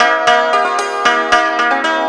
[ ]MORINHUR YCH.s3p2021-08-01 01:42 5.8K